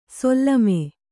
♪ sollame